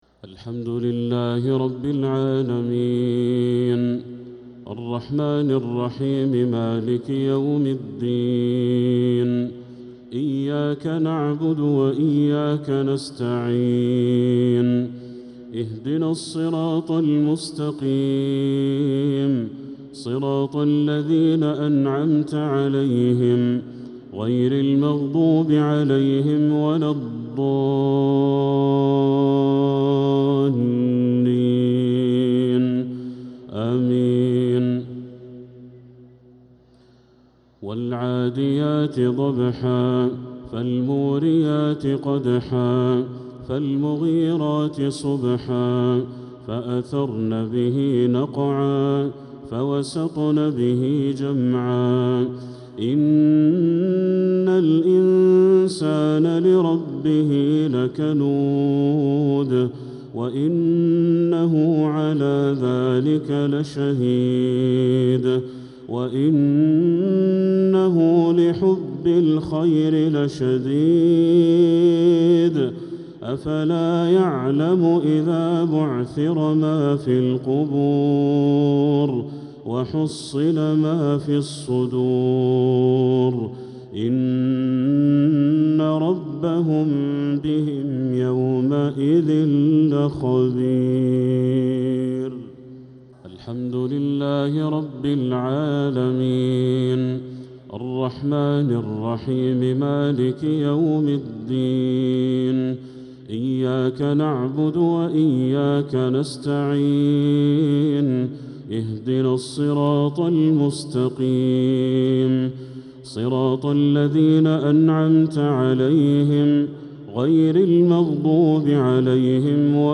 عشاء الثلاثاء 4-9-1446هـ سورتي العاديات و قريش كاملة | Isha prayer Surat al-`Adiyat & al-Quraish 4-3-2025 > 1446 🕋 > الفروض - تلاوات الحرمين